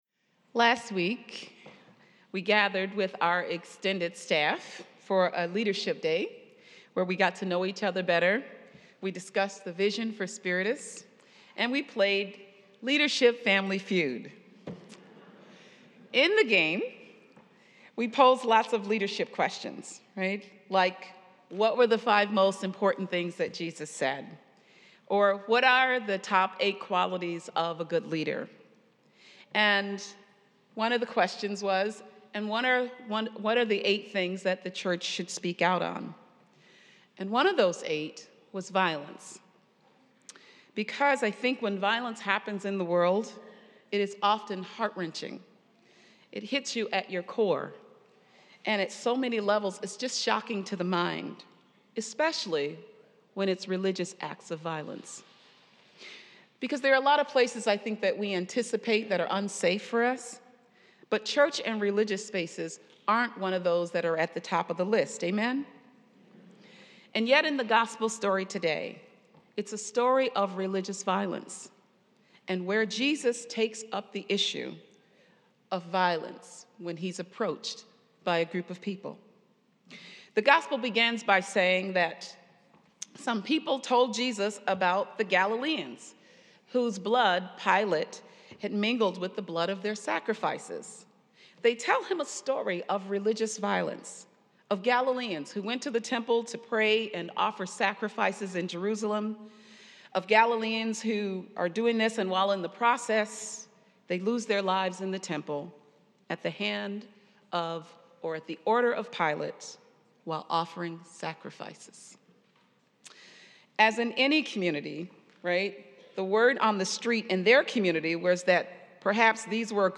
Homily Transcript Last week, we gathered with our extended staff for a leadership day where we got to know each other better, we discussed the vision for Spiritus, and played leadership Family Feud.